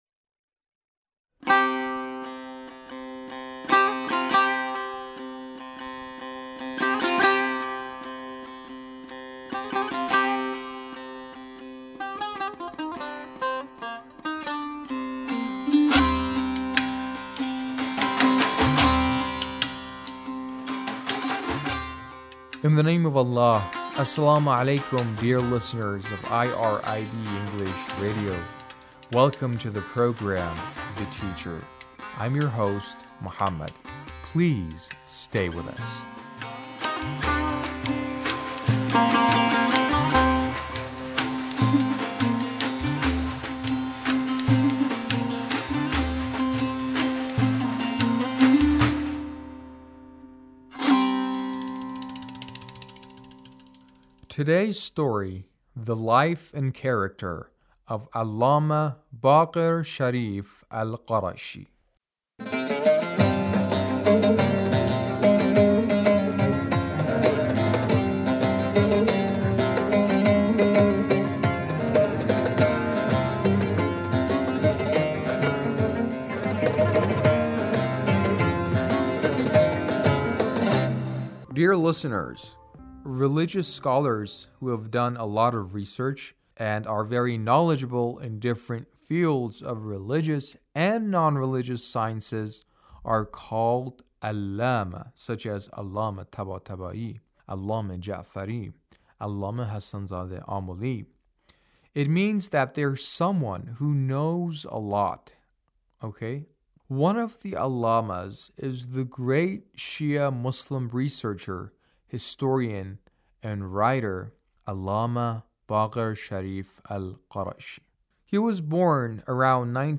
A radio documentary on the life of Allamah Sharif Al-Qarashi